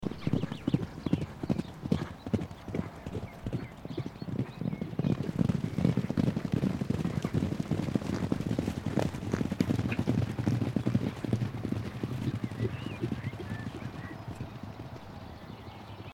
wild horses running
Category 🐾 Animals